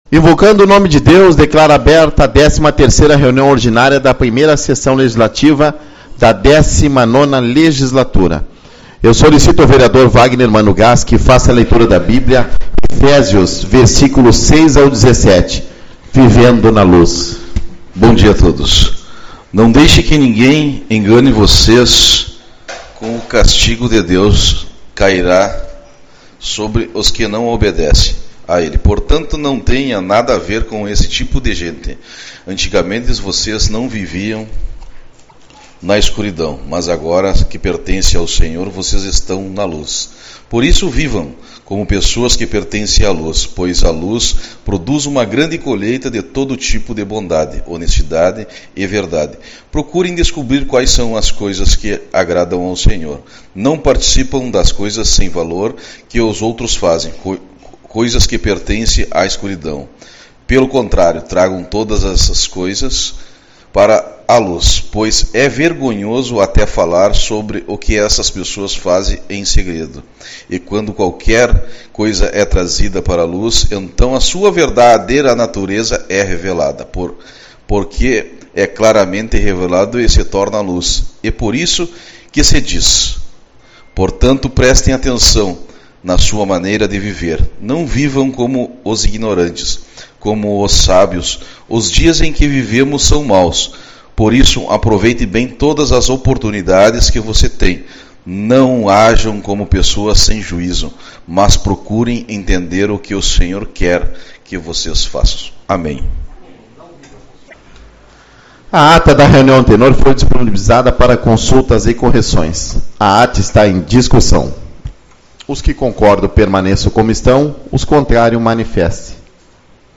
Tipo de Sessão: Ordinária